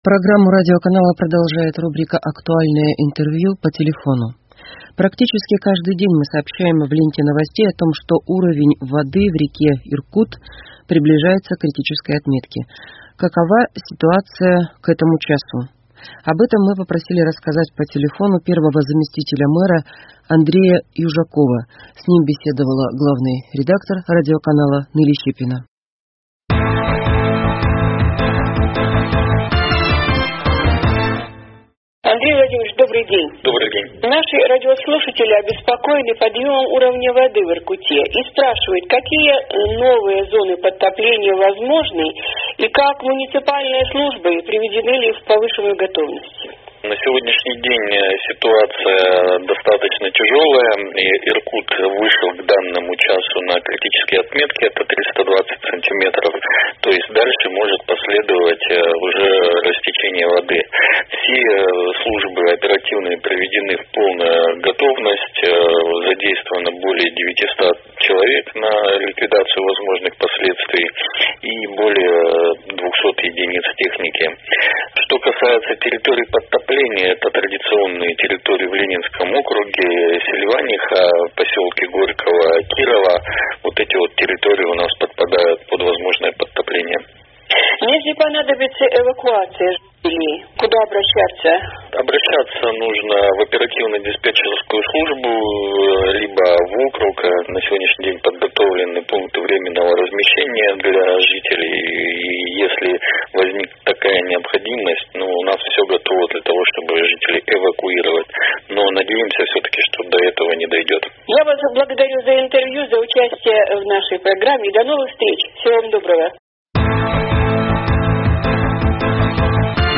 Актуальное интервью: Паводковая ситуация в Иркутске 25.08.2021